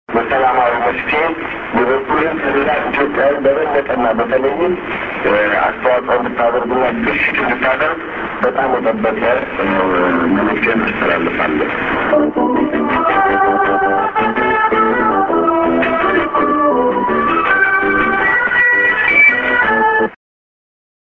End NoID->music